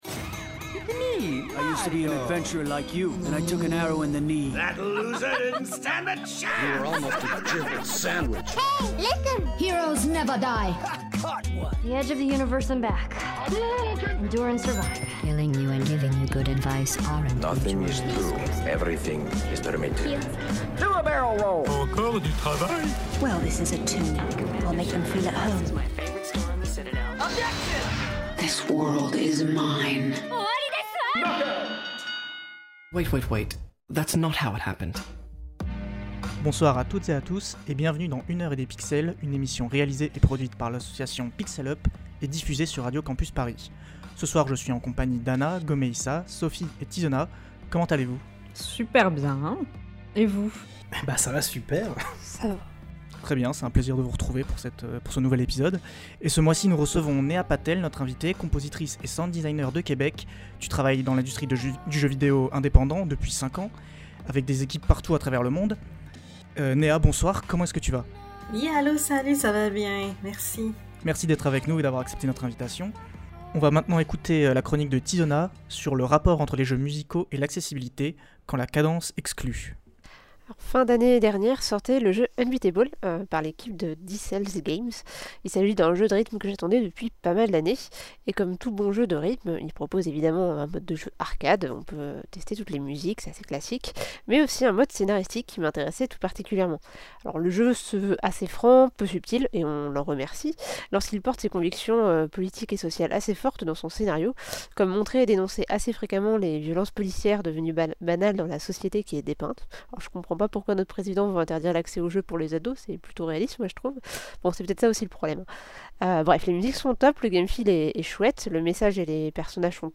Émission diffusée le 21 février 2026 sur Radio Campus Paris.
Type Magazine Culture